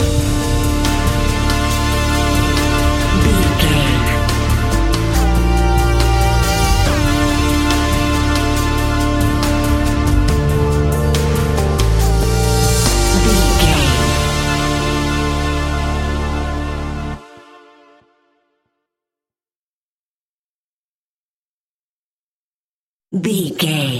Thriller
Aeolian/Minor
scary
ominous
dark
haunting
eerie
synthesiser
percussion
drums
suspenseful
electronic music